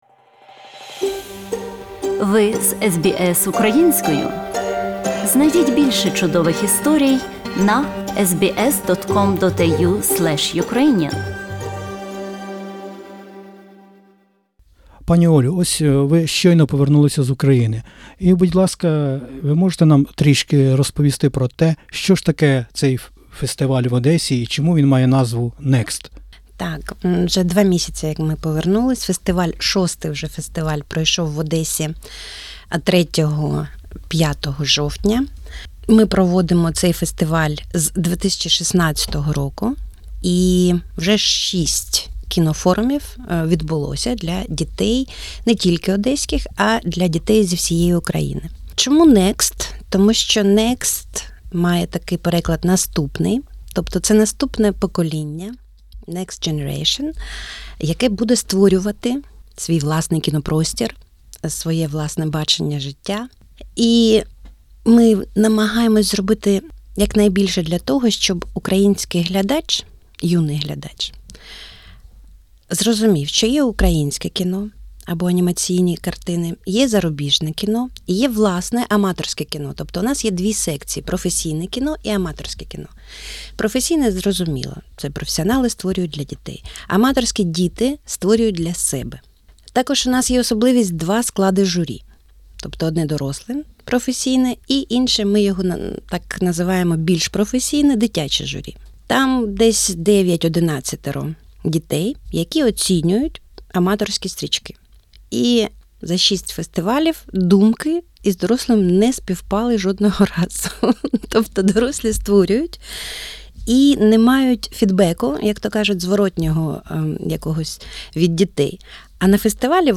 веде розмову